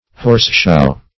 horseshow \horse"show`\